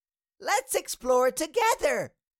Cartoon Little Child, Voice, Lets Explore Together Sound Effect Download | Gfx Sounds
Cartoon-little-child-voice-lets-explore-together.mp3